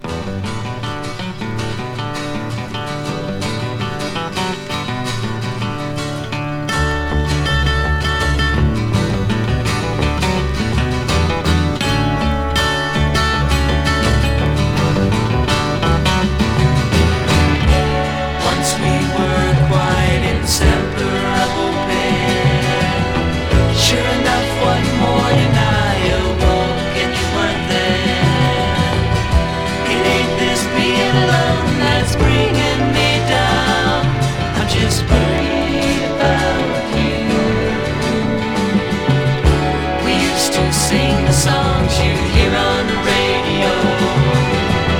Rock, Pop, Soft Rock, Vocal　USA　12inchレコード　33rpm　Stereo